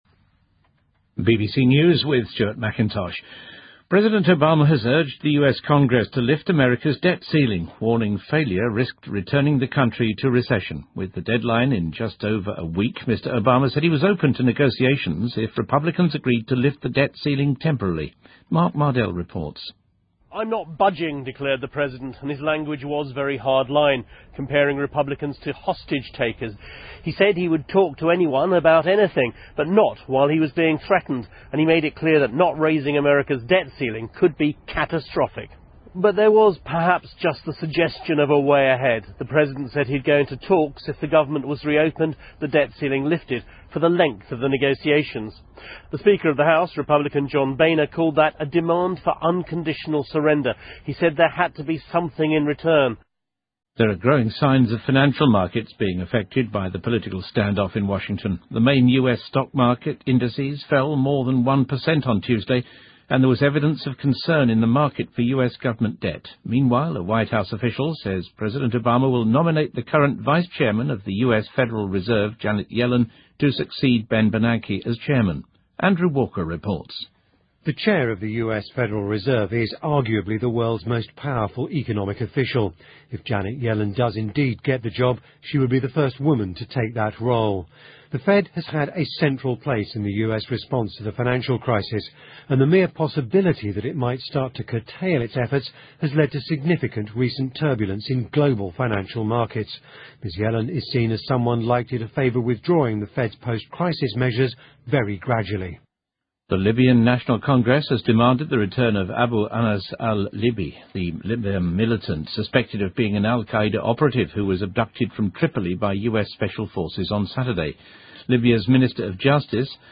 BBC news,总统奥巴马敦促美国国会取消美国债务限额